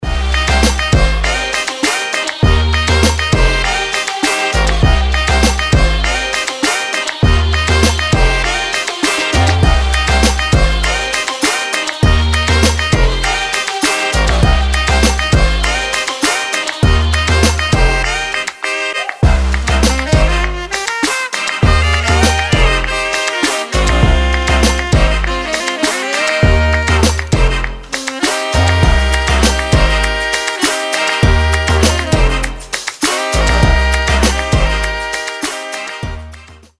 dance
Electronic
без слов
Приятная электронная музыка без слов